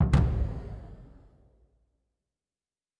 Special Click 25.wav